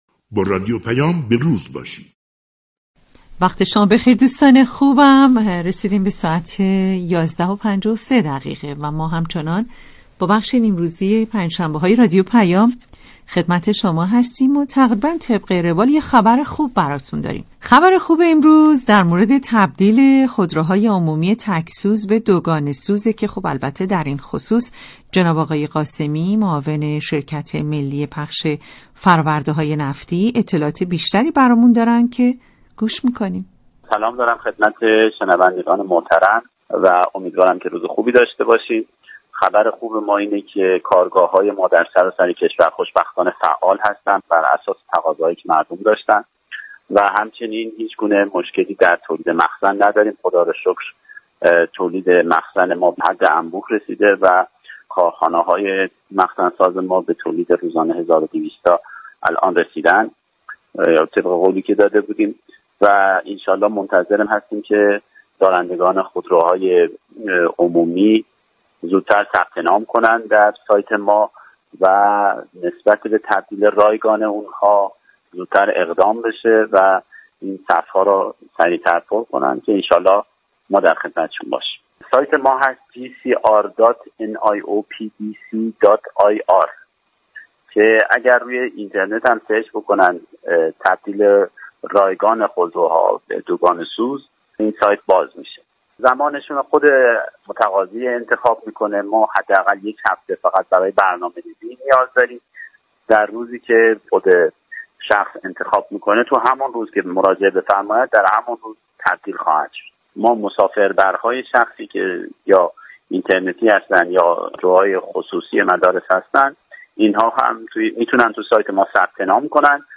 در گفتگو با رادیو پیام